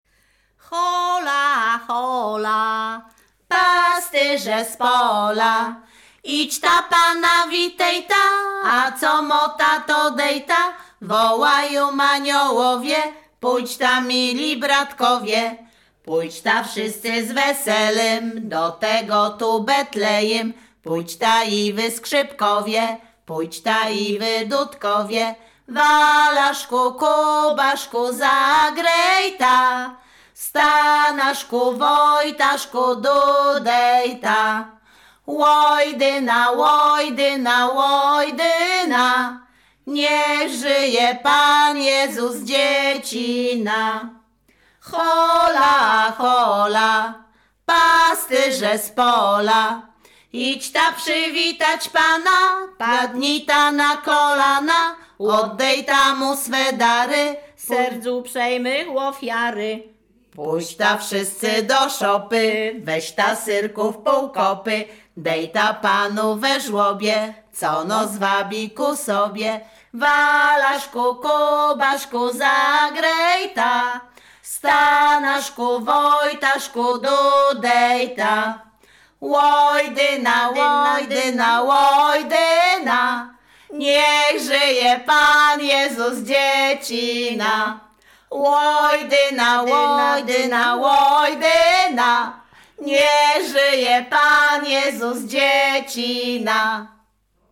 Śpiewaczki z Chojnego
Sieradzkie
Pastorałka